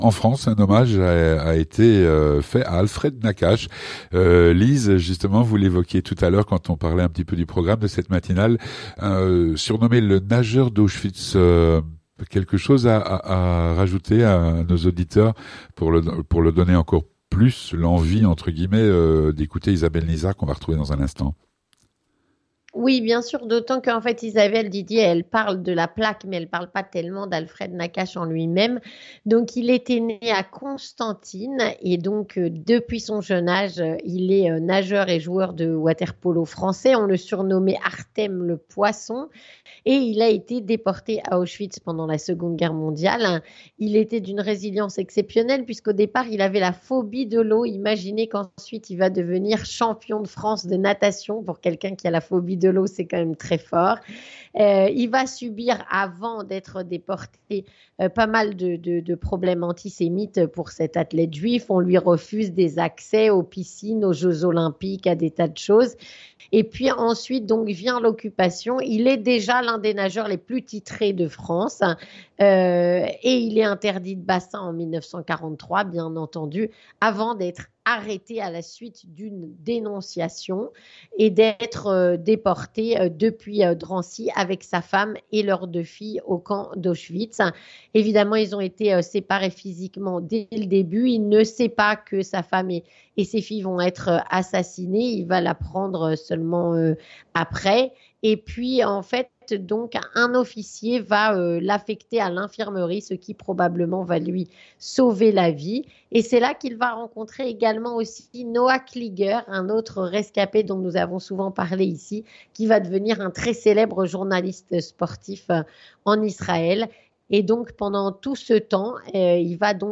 Avec Isabelle Nizard, adjointe au maire du XVIème arrondissement de Paris